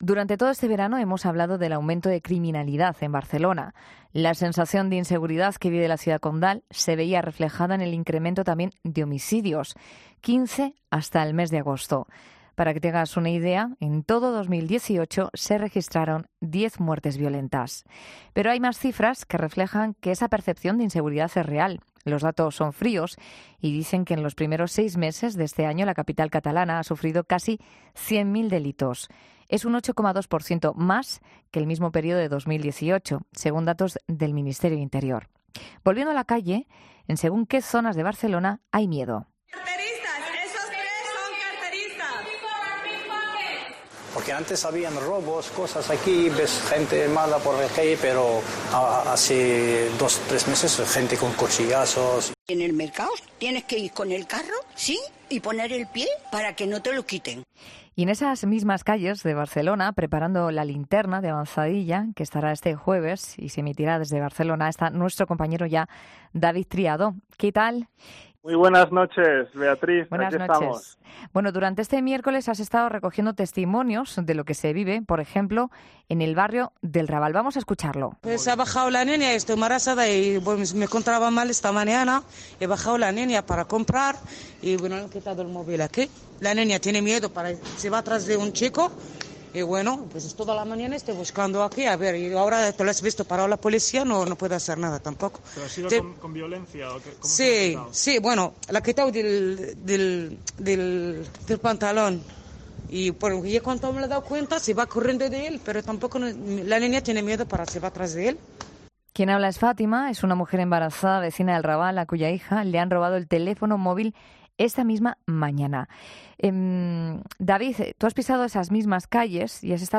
En ‘La Noche’ de COPE nos vamos hasta las calles de uno de los distritos más conflictivos: el del Raval.